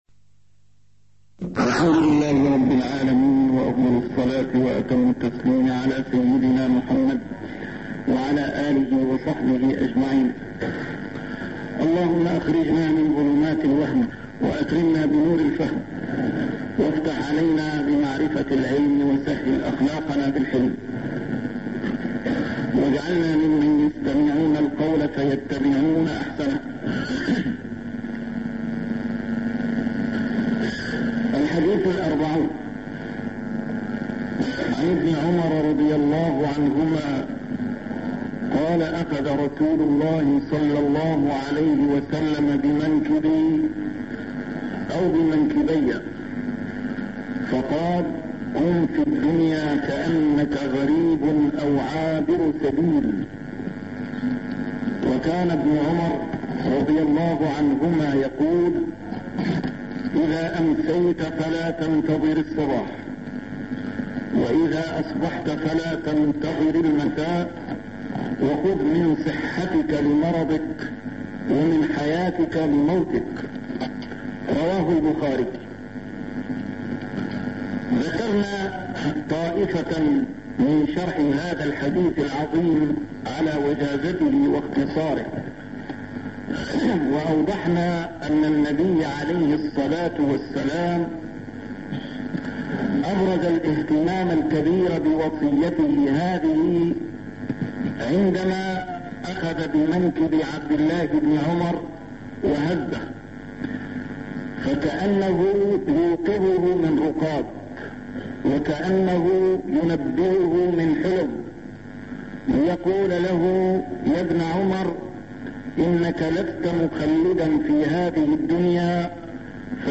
A MARTYR SCHOLAR: IMAM MUHAMMAD SAEED RAMADAN AL-BOUTI - الدروس العلمية - شرح الأحاديث الأربعين النووية - تتمة شرح الحديث الأربعين: حديث ابن عمر (كُنْ في الدنيا كأنك غريب أو عابِرُ سبيل) 136